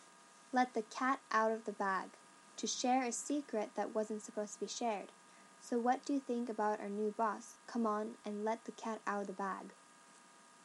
英語ネイティブによる発音は下記のリンクをクリックしてください。
LetTheCatOutOfTheBag.mp3